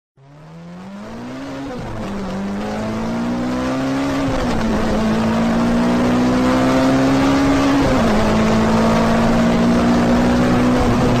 دانلود آهنگ صدای گاز دادن ماشین 3 از افکت صوتی حمل و نقل
جلوه های صوتی
برچسب: دانلود آهنگ های افکت صوتی حمل و نقل دانلود آلبوم صدای گاز دادن ماشین – توربو و انواع مختلف از افکت صوتی حمل و نقل